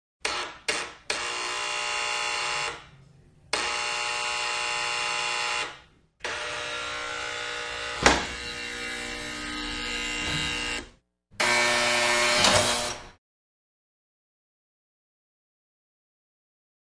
Door Buzzer, Apartment Security Type; Loud Midrange Buzz With Door Latch Open.